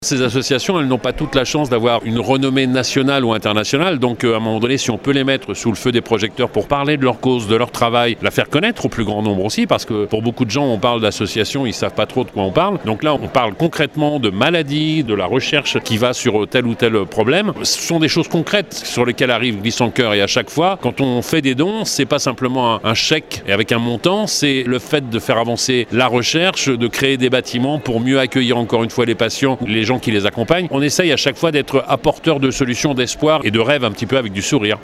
Glisse en cœur joue un rôle très important pour ces associations comme le rappelle le parrain historique de Glisse en Coeur, l’animateur télé, Stéphane Thébaut :